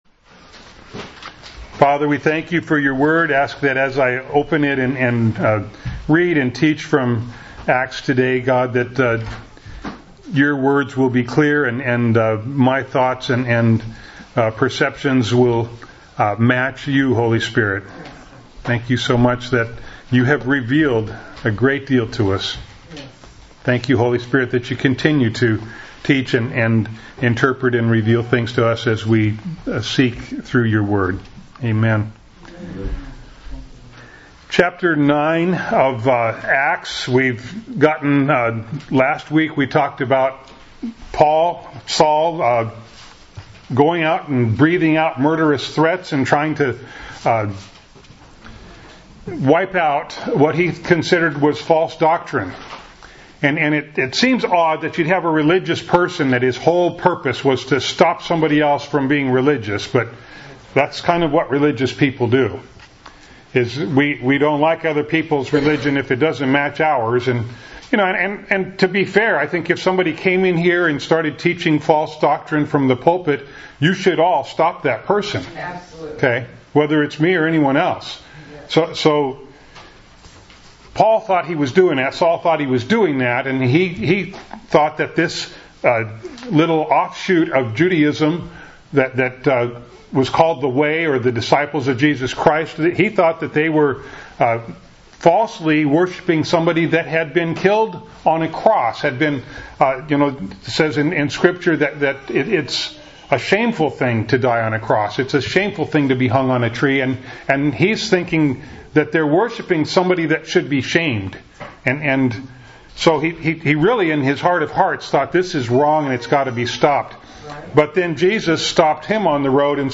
Acts 9:19b-31 Service Type: Sunday Morning Bible Text